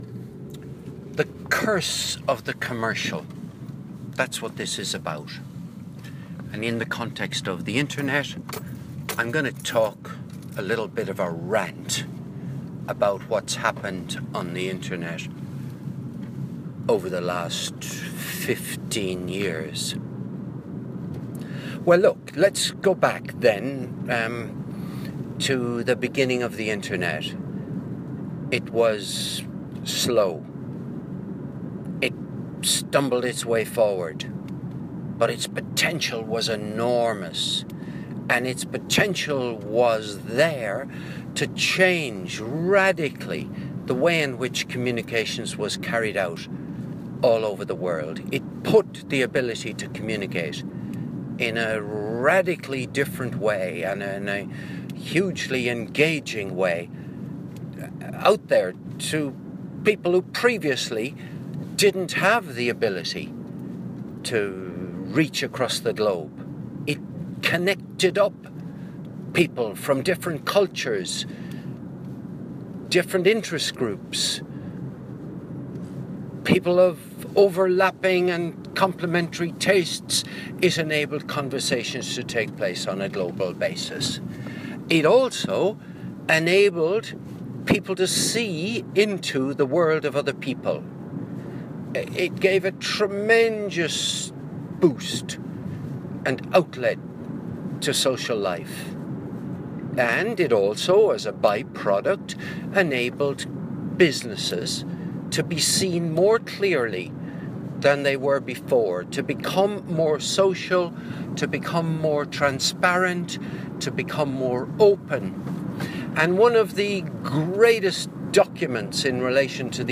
The curse of the commercial - Rant